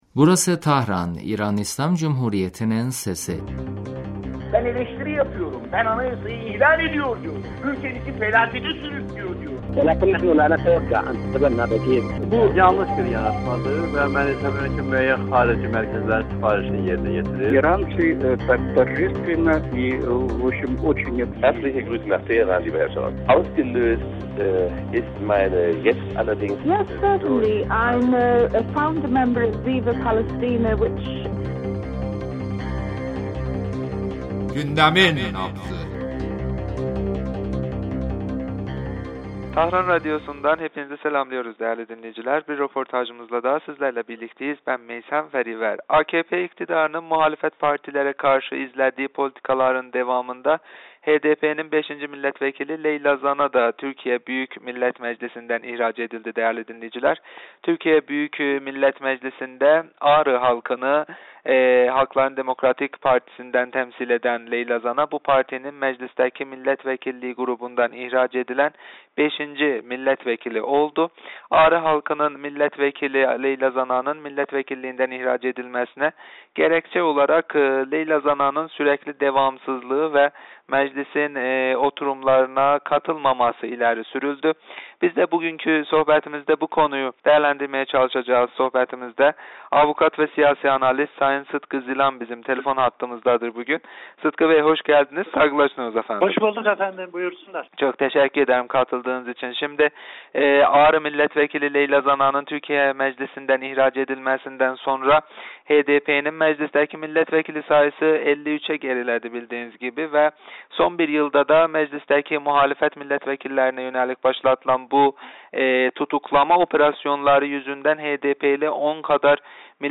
radyomuza verdiği demecinde HDP'ye yönelik baskıların artması ve Leyla Zana'nın tutuklanması konuları üzerinde görüşlerini bizimle paylaştı.